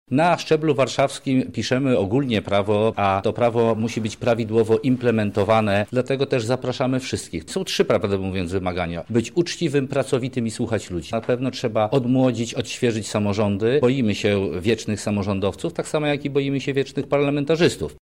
• mówi poseł Jarosław Sachajko.